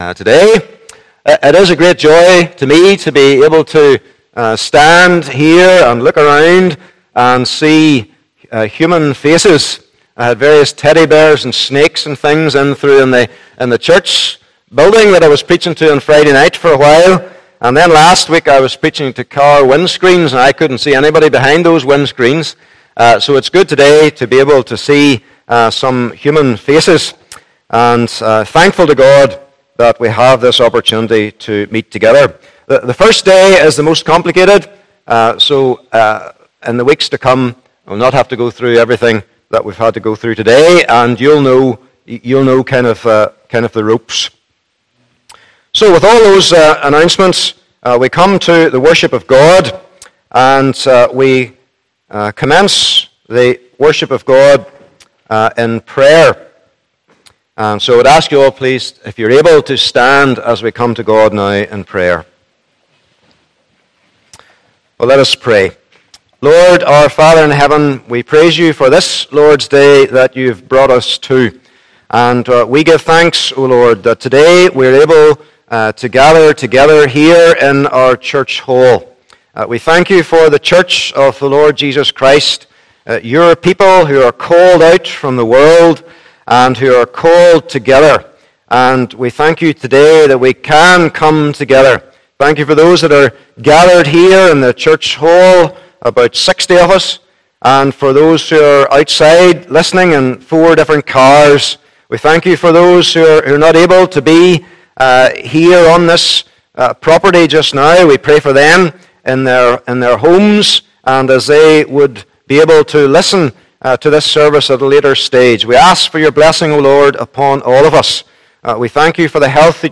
11- 12 Service Type: Morning Service Bible Text